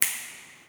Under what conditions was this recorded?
An impulse response is created by playing a sound, or an impulse, in a space.